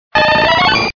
Fichier:Cri 0049 DP.ogg
contributions)Televersement cris 4G.